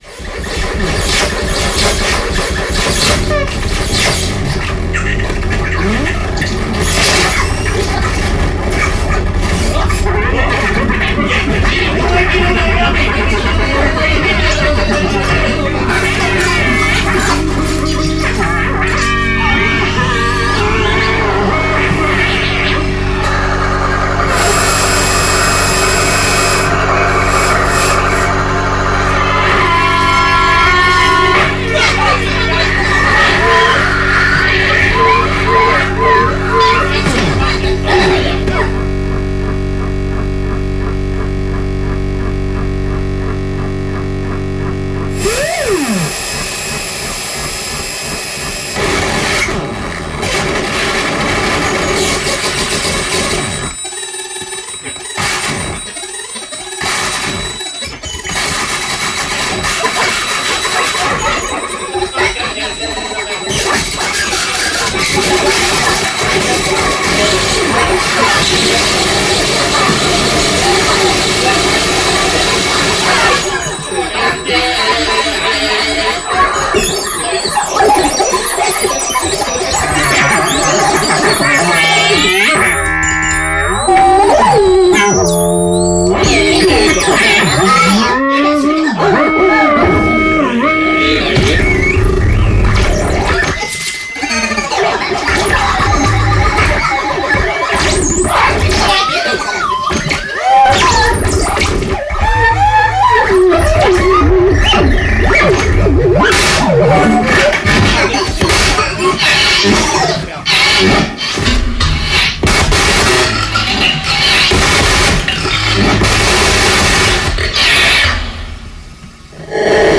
そこには、全く新しい音のバランスが現れている。
二人で演奏しているのに手が四本ある個人が演奏しているようにも錯覚してしまう。